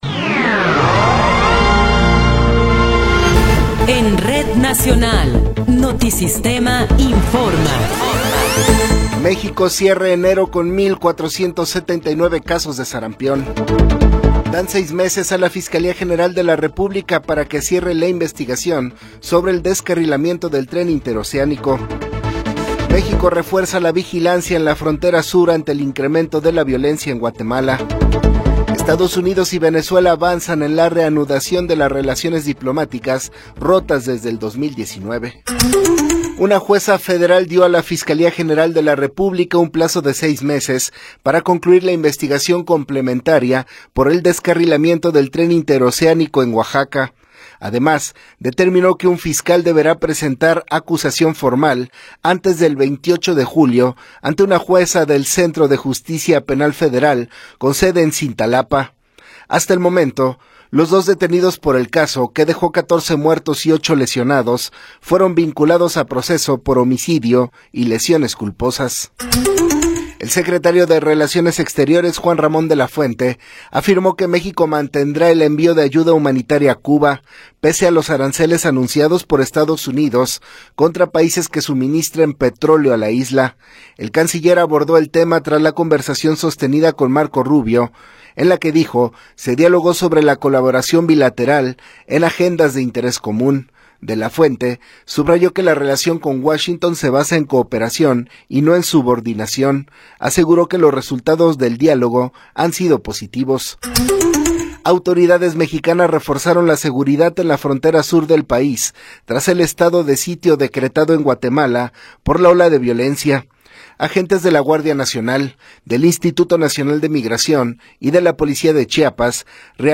Noticiero 14 hrs. – 31 de Enero de 2026
Resumen informativo Notisistema, la mejor y más completa información cada hora en la hora.